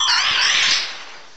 cry_not_minior.aif